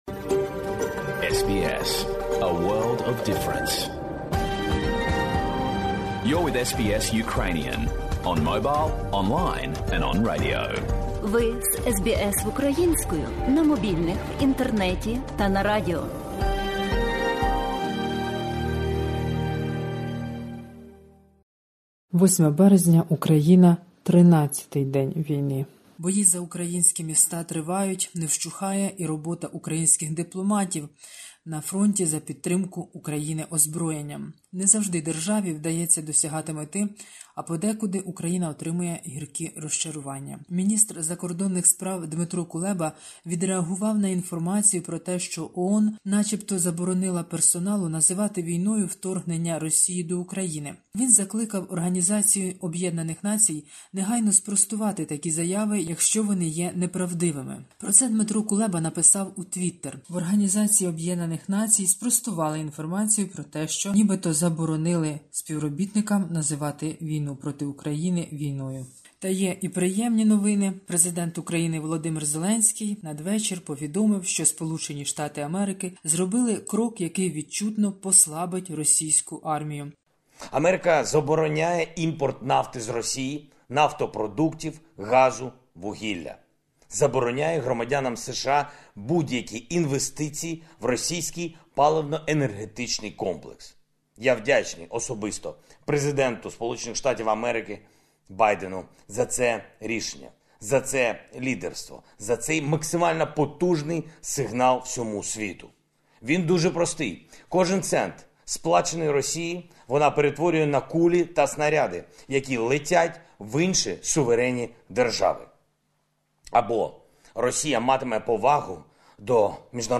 Новини з України спеціально для радіослухачів SBS Ukrainian.